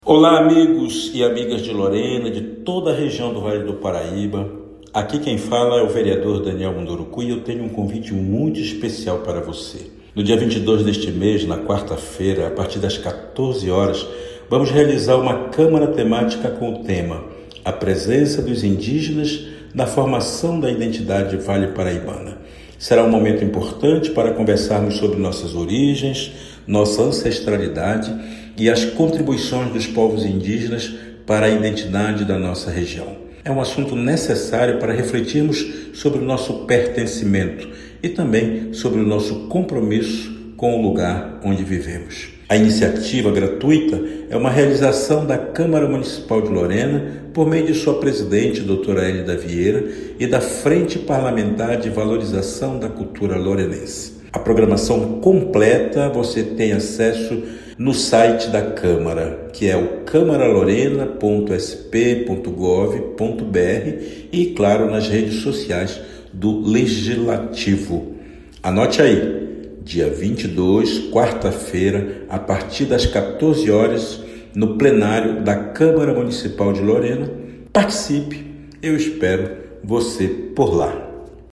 Entrevista (áudio):
O vereador Daniel Munduruku (PDT) convida a população a participar da Câmara Temática.